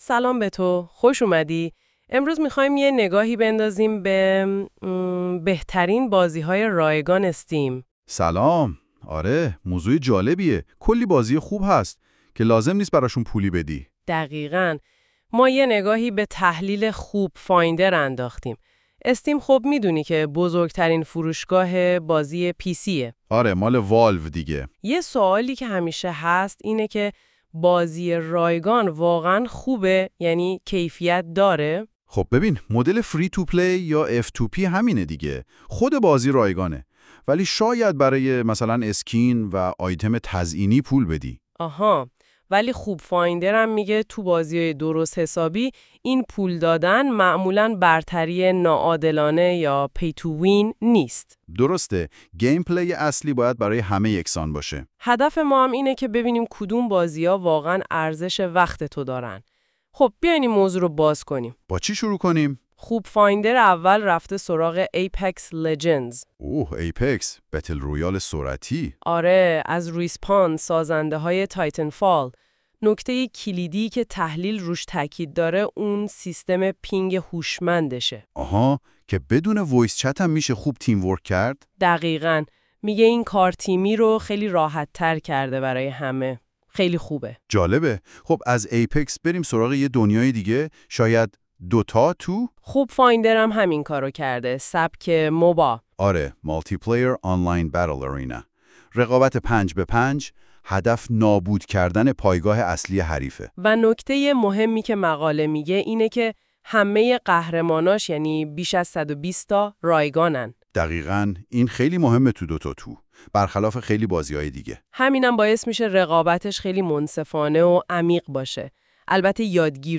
🎧 خلاصه صوتی بهترین بازی های ویدئویی رایگان در استیم که باید امتحان کنید
این خلاصه صوتی به صورت پادکست و توسط هوش مصنوعی تولید شده است.